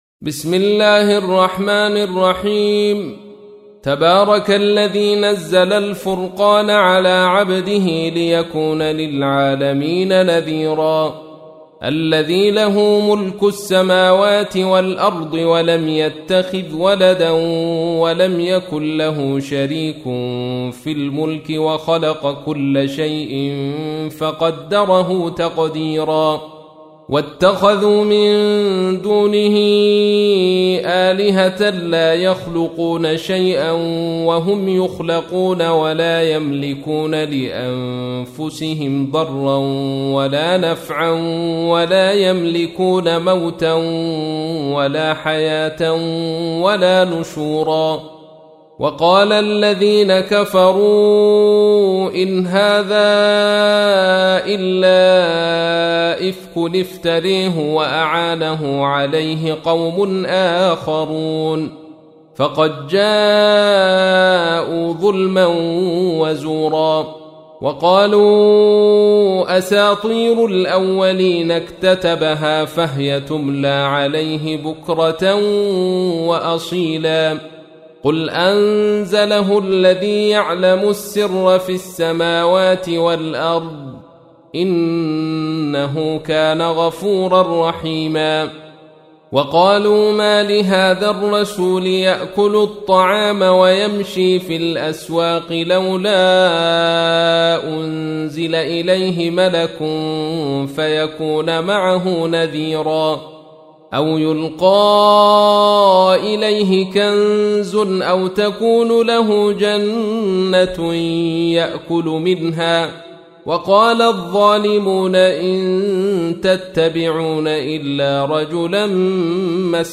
تحميل : 25. سورة الفرقان / القارئ عبد الرشيد صوفي / القرآن الكريم / موقع يا حسين